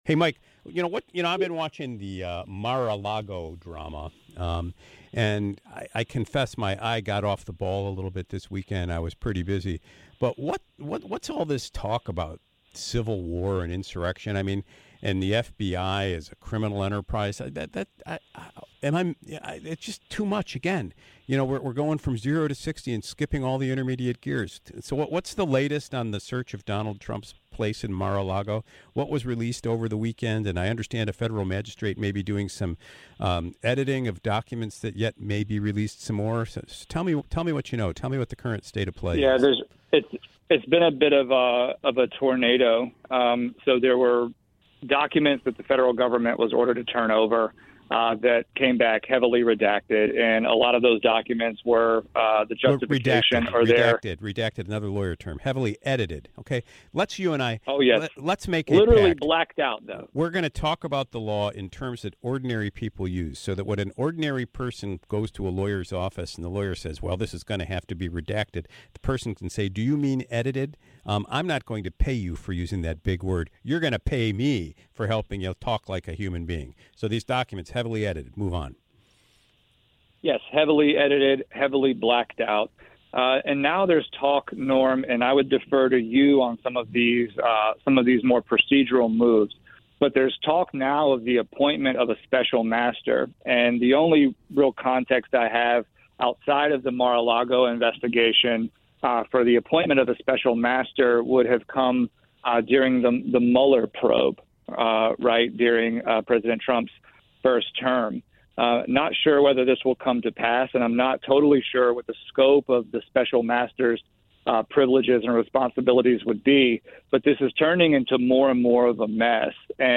Included were some callers.